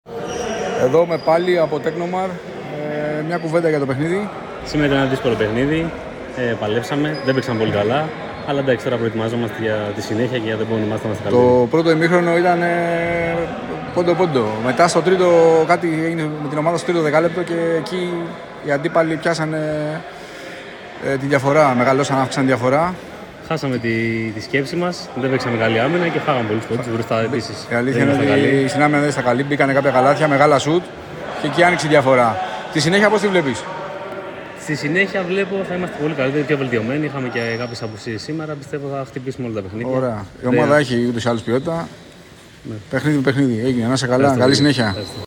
GAMES INTERVIEWS: